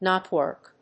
アクセント・音節knót・wòrk